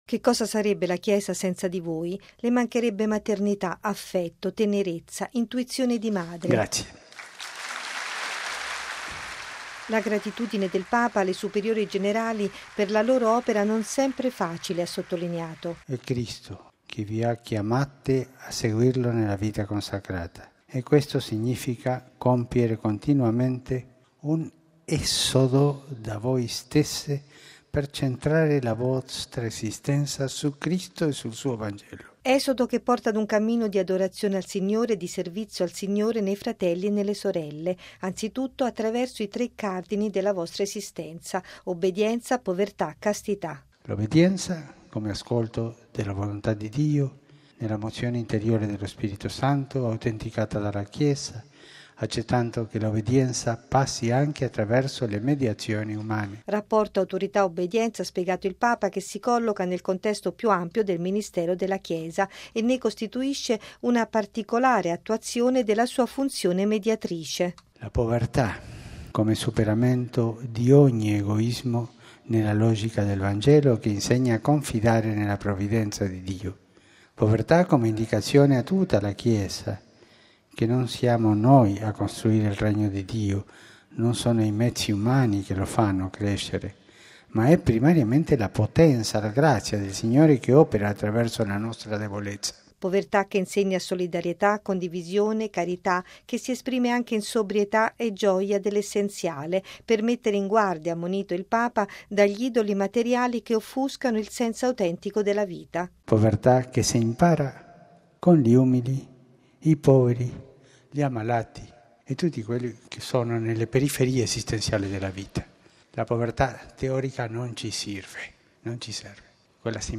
◊   “Siate gioiose, perché è bello seguire Gesù”: così Papa Francesco alle superiori generali di tutto il mondo - oltre 800 di 75 Paesi in rappresentanza di circa 700 mila religiose - ricevute stamane nell’Aula Paolo VI, in chiusura della loro Assemblea plenaria, conclusasi ieri a Roma sul tema “Il servizio dell’autorità secondo il Vangelo”.